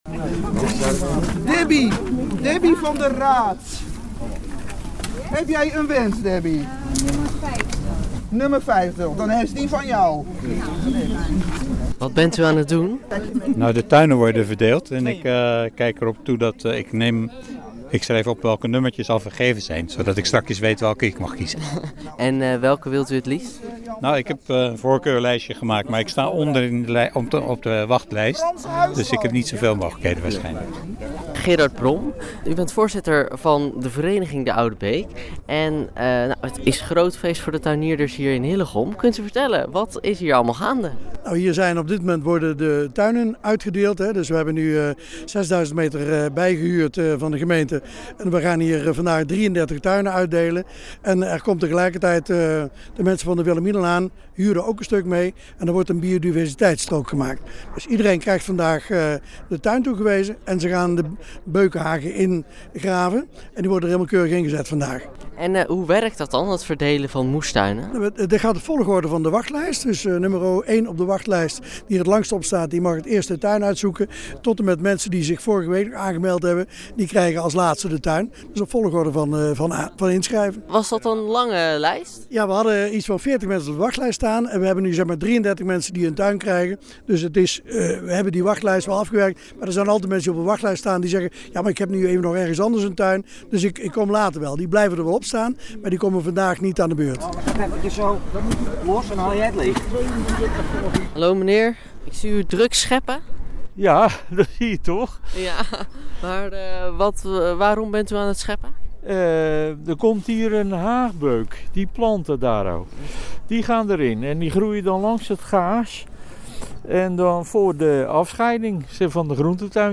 Hillegom – De een stond al bijna tien jaar op de wachtlijst en de ander nog maar een paar weken, maar vanaf dit moment hebben drieëndertig Hillegomse gezinnen er een tuintje bij om voor te zorgen. Volkstuinvereniging de Oude Beek verdeelde drieëndertig moestuintjes waarna de enthousiaste tuinders gelijk aan de slag gingen.